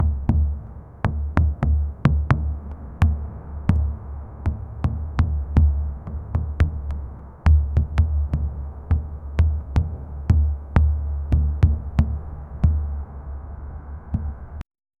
Deep drums: slow, distant, barely audible beats, like an echo of an ancestral ritual. 0:15 Created Apr 29, 2025 10:56 PM Short & rhythmic jingle for logo animation for YouTube channel.
sound-of-distant-drums-si-knsqc5ng.wav